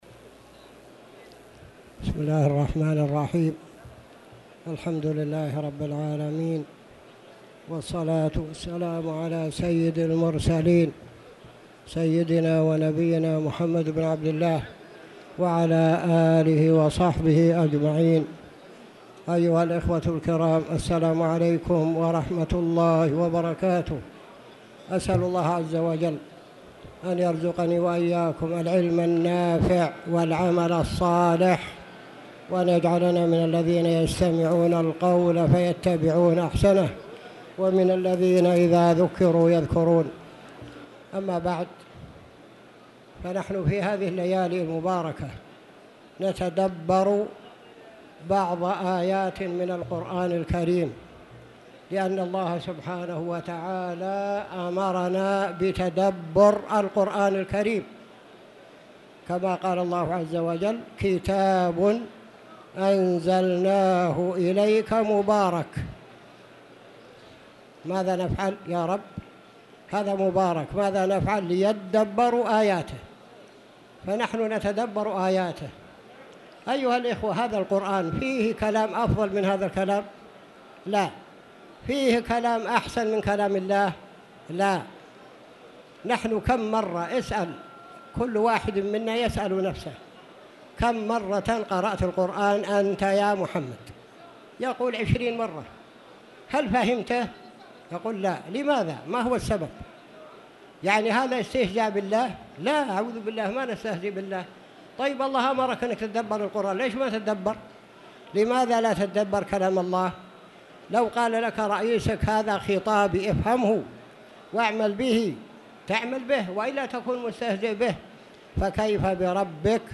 تاريخ النشر ٢٢ رمضان ١٤٣٧ هـ المكان: المسجد الحرام الشيخ